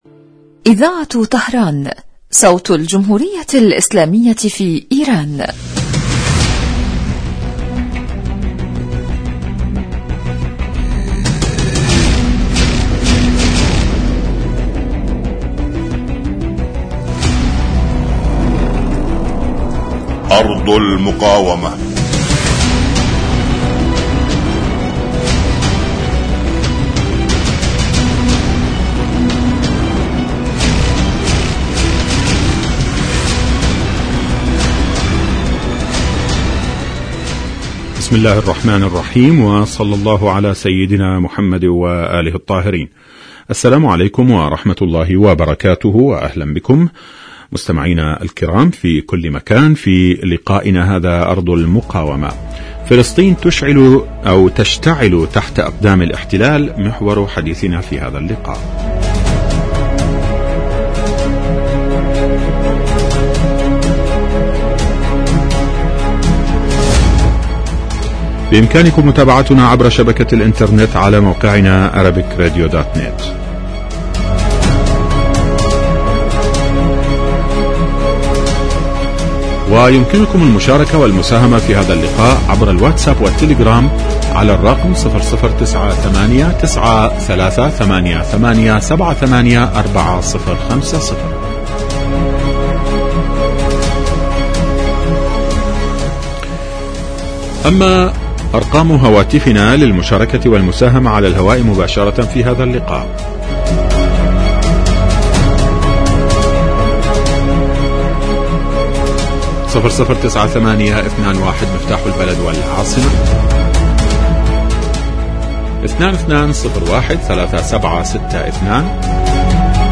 برنامج إذاعي حي لنصف ساعة يتناول بالدراسة والتحليل آخر التطورات والمستجدات على صعيد سوريا والأردن وفلسطين المحتلة ولبنان.
يستهل المقدم البرنامج بمقدمة يعرض فيها أهم ملف الأسبوع ثم يوجه تساؤلاته إلى الخبراء السياسيين الملمين بشؤون وقضايا تلك الدول والذين تتم استضافتهم عبر الهاتف .